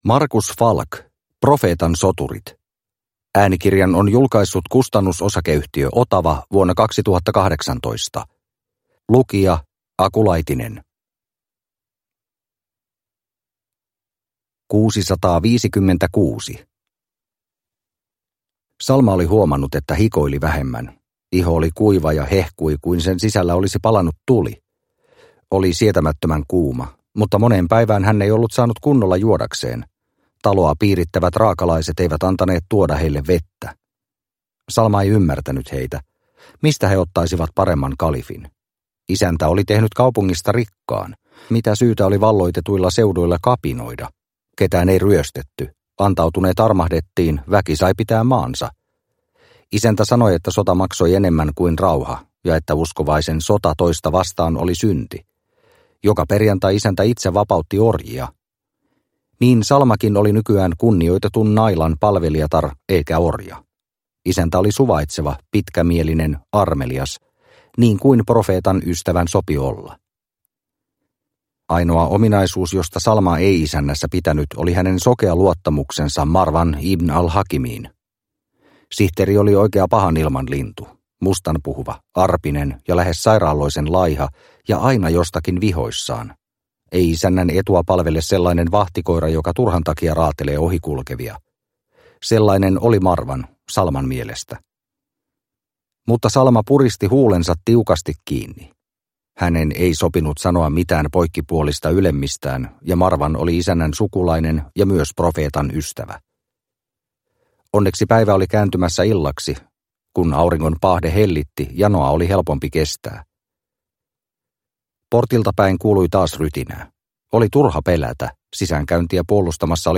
Profeetan soturit – Ljudbok – Laddas ner